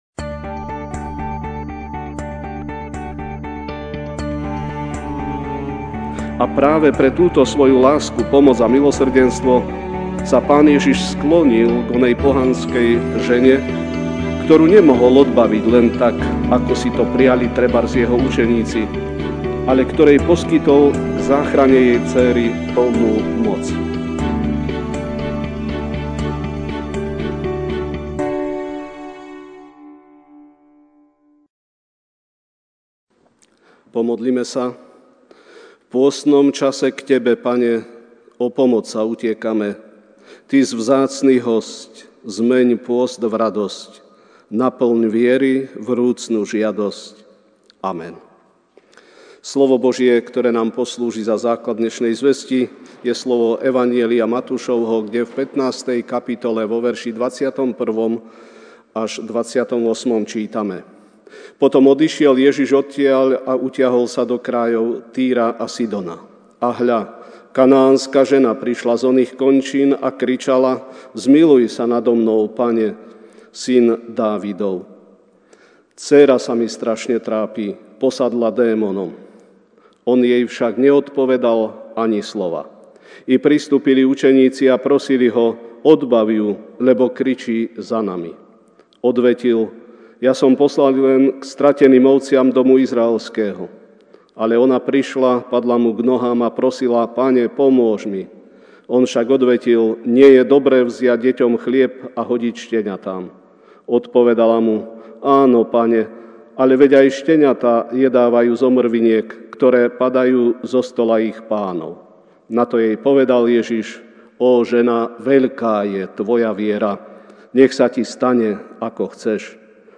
mar 17, 2019 Vytrvalá prosba MP3 SUBSCRIBE on iTunes(Podcast) Notes Sermons in this Series Večerná kázeň: Vytrvalá prosba (Mt 15, 21-28) Potom odišiel Ježiš a utiahol sa do krajov Týru a Sidonu.